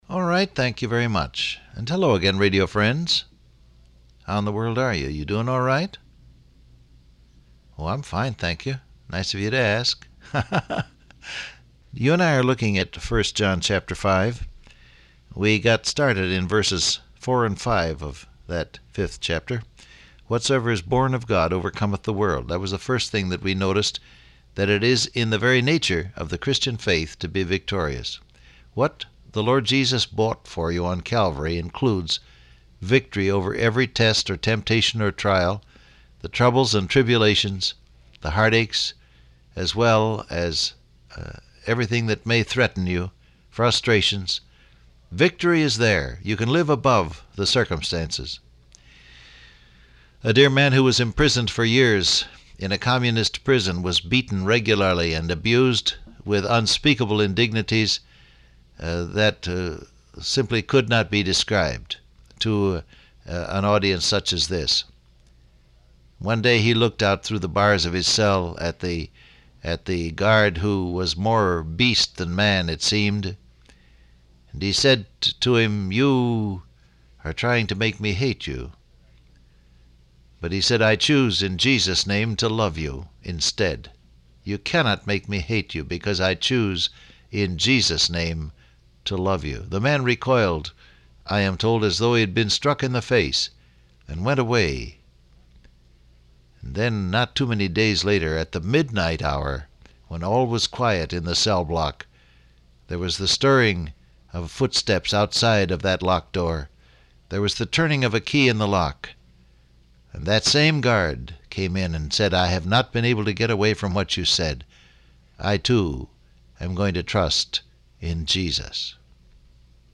Download Audio Print Broadcast #7360 Scripture: 1 John 5:4-5 , 1 John 1:7 Topics: Pray , Walk In The Light , Born Of God , Victorious Transcript Facebook Twitter WhatsApp Alright, thank you very much.